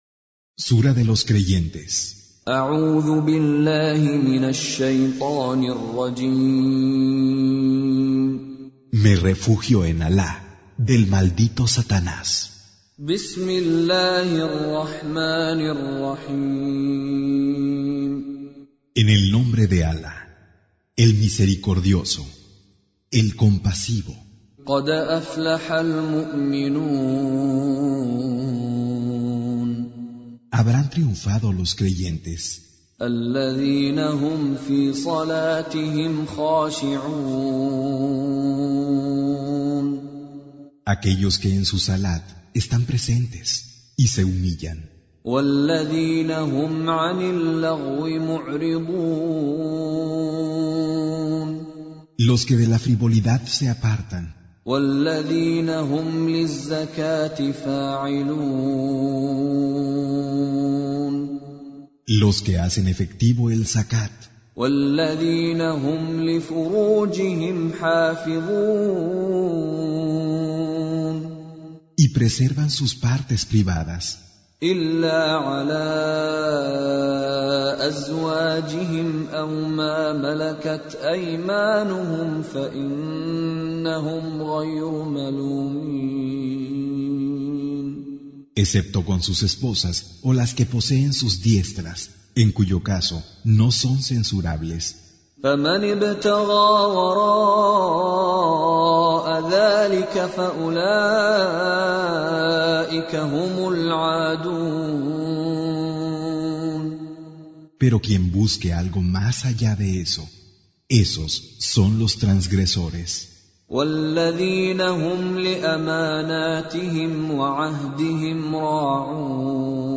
Con Reciter Mishary Alafasi
Surah Sequence تتابع السورة Download Surah حمّل السورة Reciting Mutarjamah Translation Audio for 23. Surah Al-Mu'min�n سورة المؤمنون N.B *Surah Includes Al-Basmalah Reciters Sequents تتابع التلاوات Reciters Repeats تكرار التلاوات